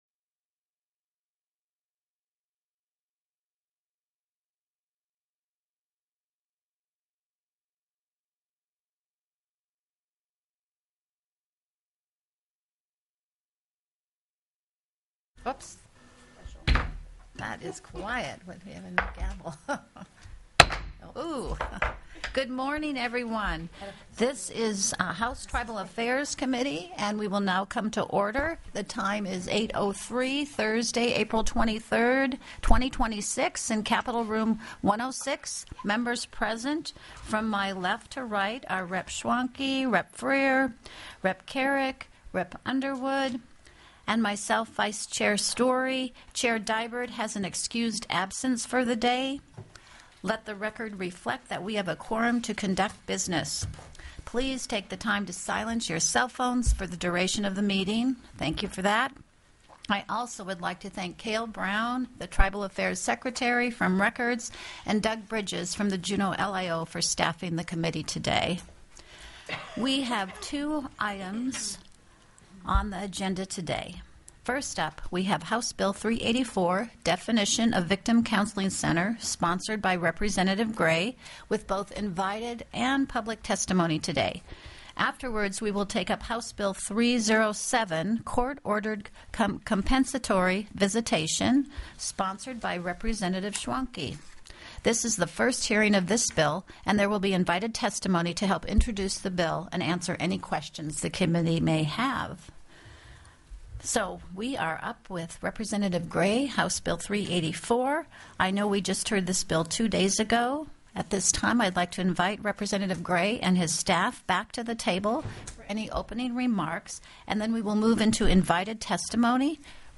The audio recordings are captured by our records offices as the official record of the meeting and will have more accurate timestamps.
HB 384 DEFINITION OF 'VICTIM COUNSELING CENTER' TELECONFERENCED
Invited & Public Testimony